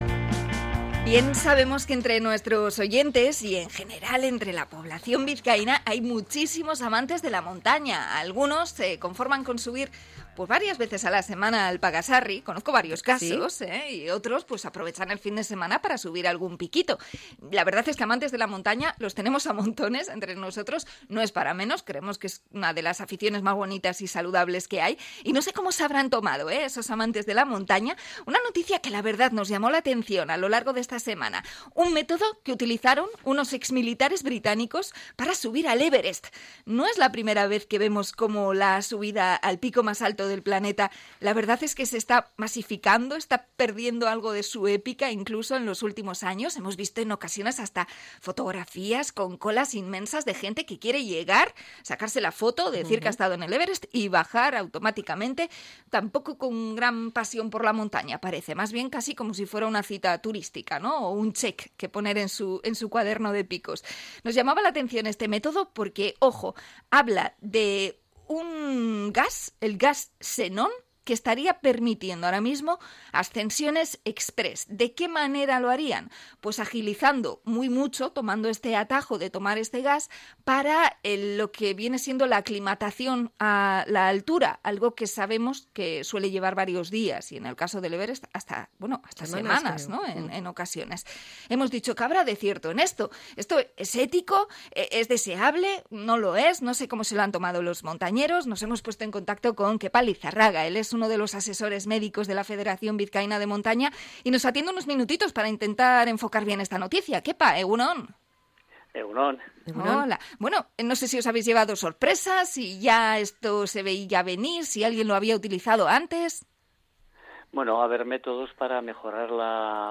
Charlamos con la Federación Bizkaina de Montaña